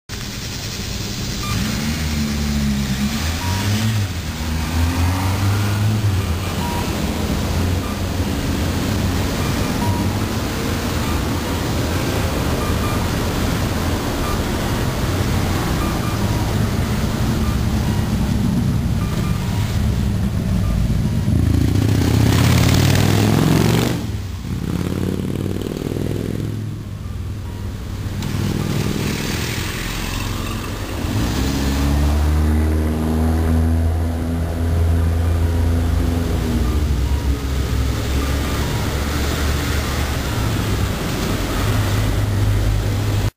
カッコーですが、聞いたときはリズムは一緒だったのですが、音質が違うような気がしました。音量が低いせいか分かりにくく、途中からわけが分からなくなっています・・・。
確かにかっこうの音質が奇妙ですね(･･;)！